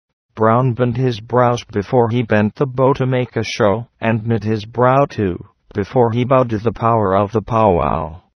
唸音